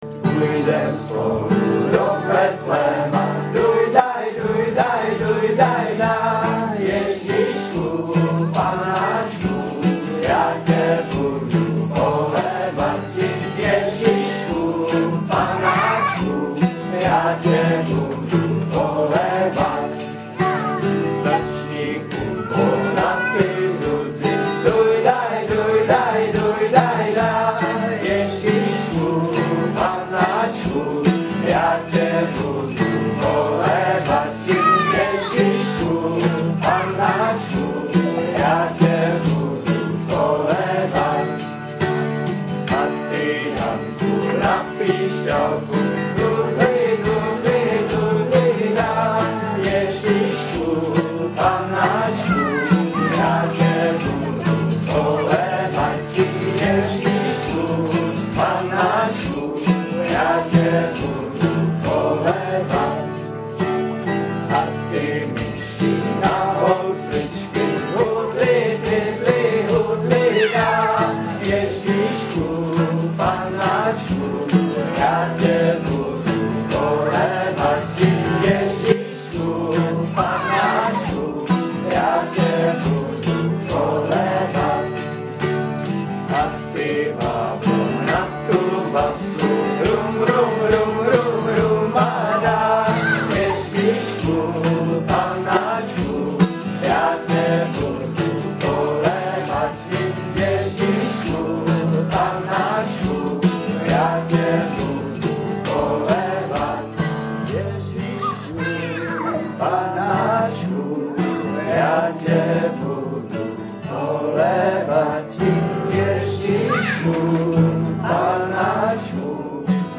VÁNOČNÍ KOLEDY